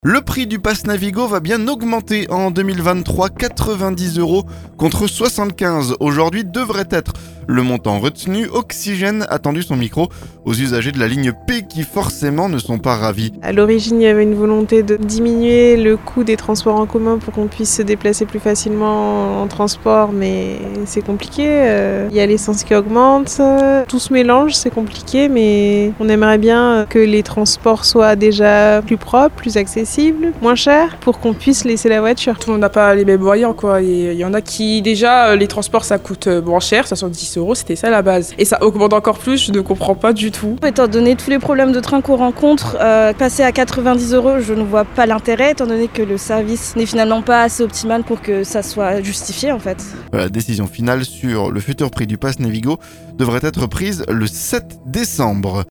Le prix du pass Navigo va bien augmenter en 2023 ! 90 euros, contre 75 aujourd'hui, devrait être le montant retenu. Oxygène a tendu son micro aux usagers de la ligne P qui, forcément, ne sont pas ravis.